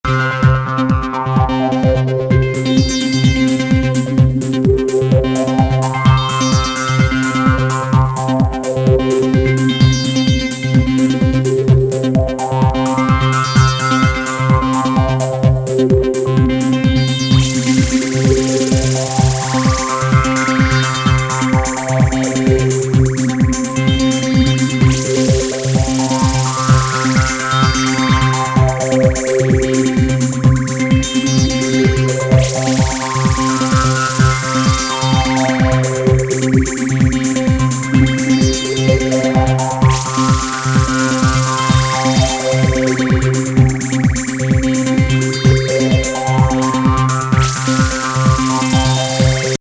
ハウスを展開する96年のエレクトリック・サウンド。
されて混沌とした心地よさを作り出します。